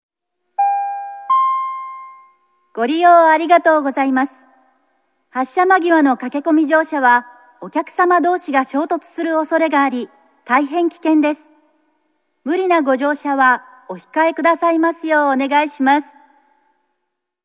大阪メトロ(大阪市営地下鉄)の啓発放送